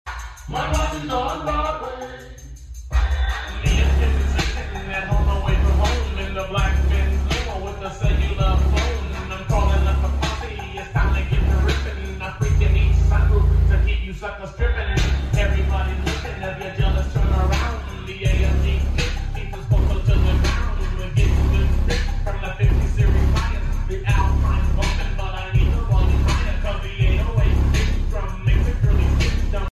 👴🏿🔊🔊🔊👑🔊🔊🔊💪🏿Old School Rap Music, 6 sound effects free download
Skar Audio VXF-‘s Sealed on 4 of my 6 Skar Audio SKv2-4500.1’s